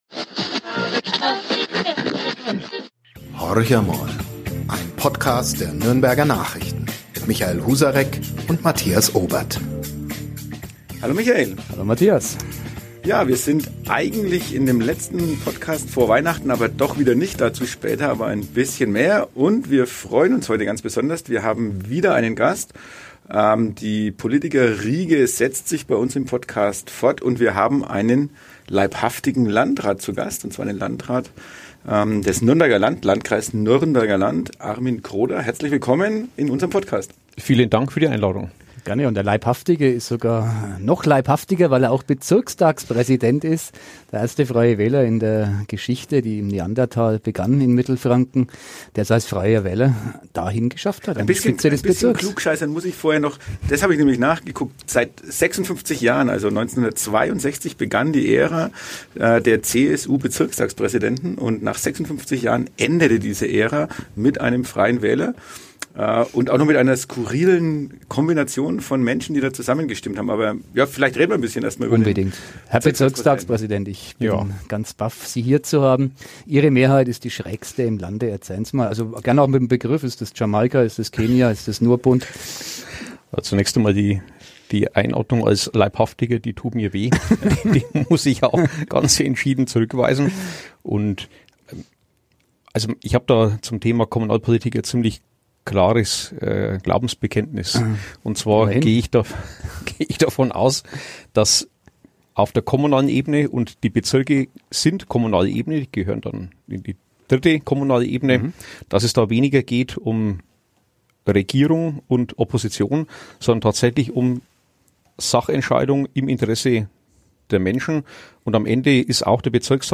Horch amol – Der NN-Podcast, Folge 95: Ein Gespräch über Macht, Respekt und Geld ~ Horch amol - Der Lokalpolitik-Podcast Podcast